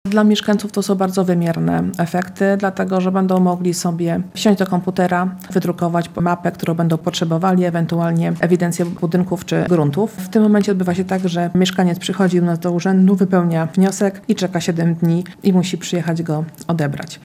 – Realizacja tego projektu zajmie około półtora roku, a cyfrowa baza danych będzie dużym ułatwieniem dla mieszkańców gminy – mówi starosta lubelski Sylwia Pisarek-Piotrowska.